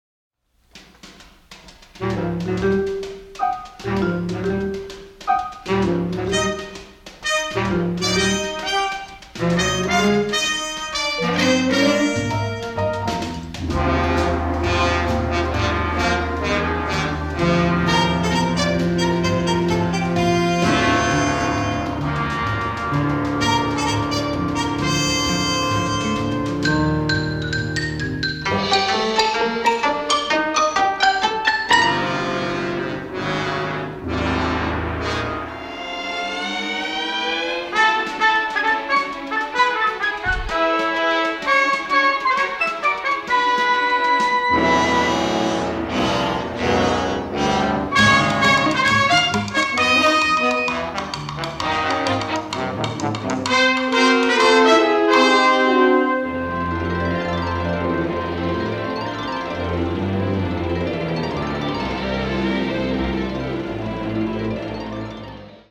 rousingly patriotic score
recognizable war anthems mixed with shades of sarcasm